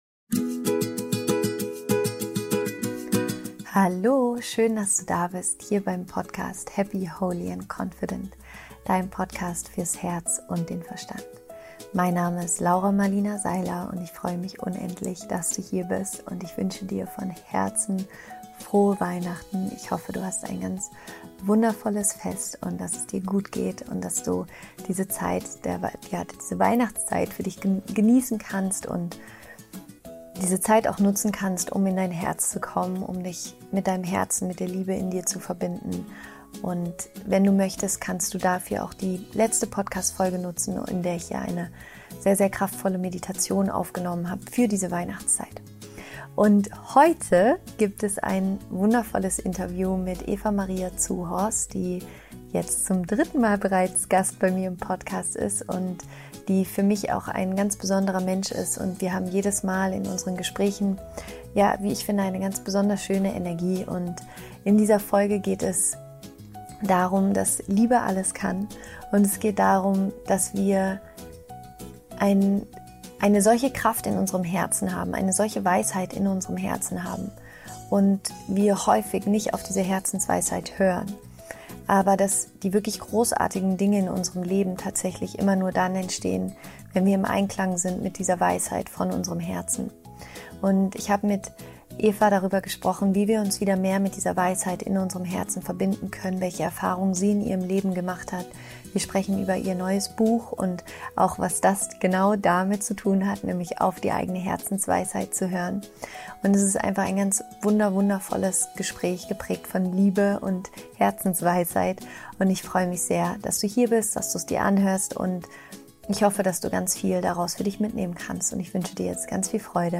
Es ist ein sehr tiefes und emotionales Gespräch und ich hoffe, dass es dich inspiriert und empowert, zusammen mit deinem Herzen deinen Weg zu gehen.